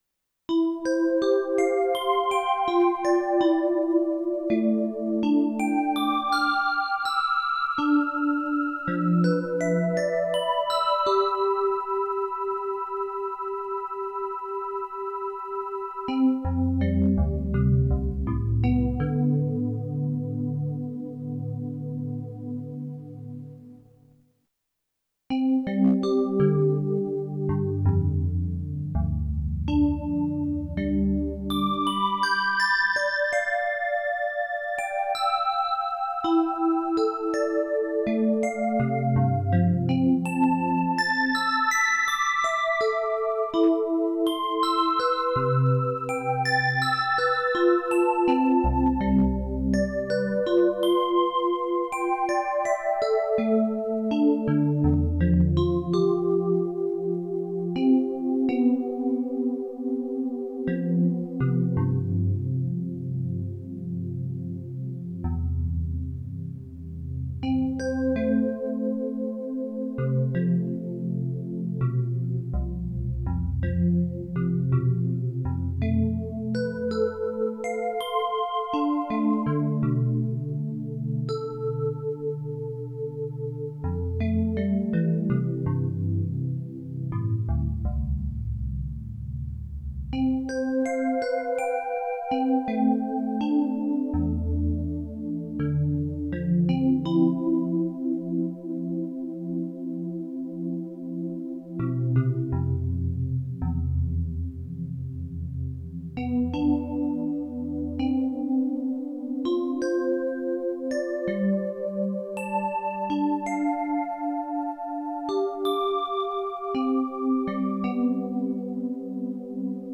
Arum enregistrée dans notre jardin botanique au couvet des fleurs à Saint Maximin le 29 octobre 2024 à partir de 10h.45
Dans l’ordre nous vous proposons d’écouter la sauge blanche seule avec les réglage habituels, puis la sauge blanche en symphonie à 5 expressions musicales mélangées.